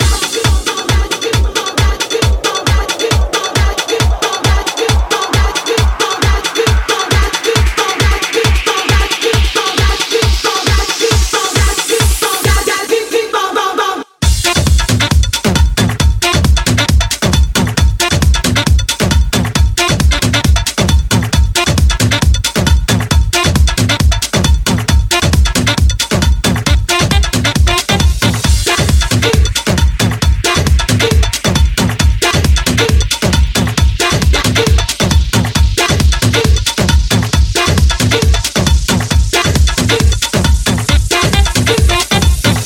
tribal - anthem - afro
Genere: tribal, jungle, anthem, afro, remix